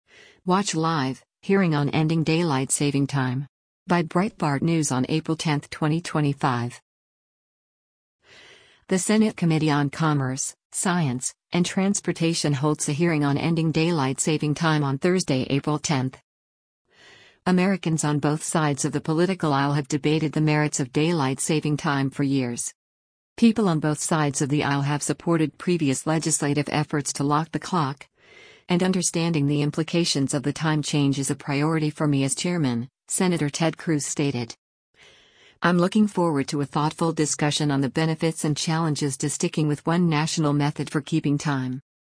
The Senate Committee on Commerce, Science, and Transportation holds a hearing on ending daylight saving time on Thursday, April 10.